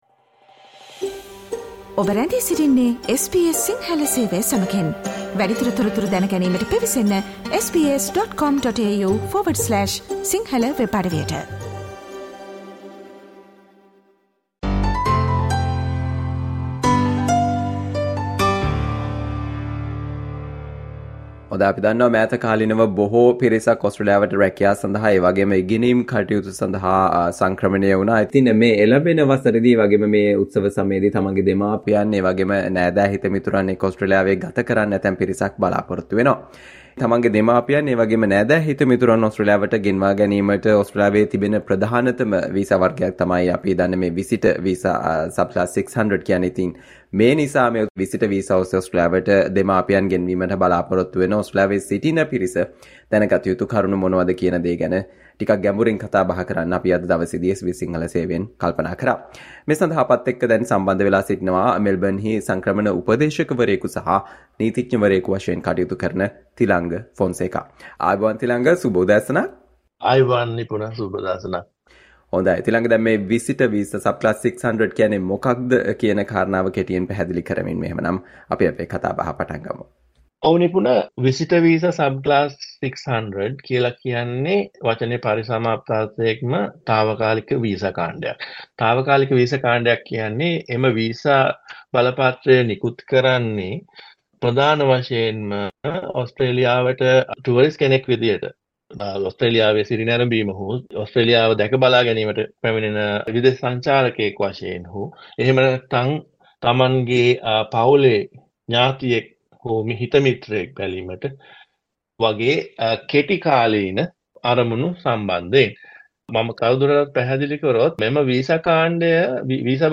SBS Sinhala discussion on things that people who are planning to visit Australia on "Visitor visa" should know